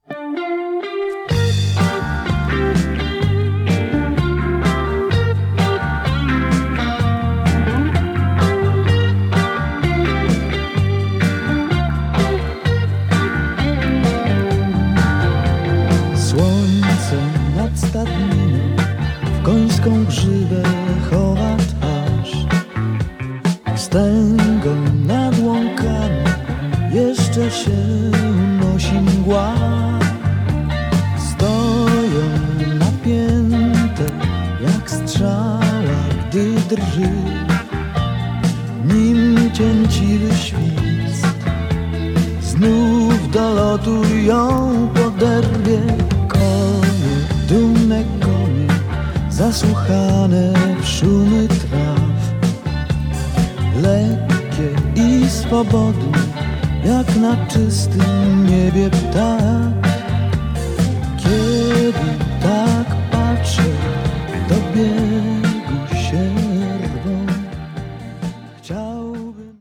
VOC GUITAR KEYB BASS DRUMS TEKST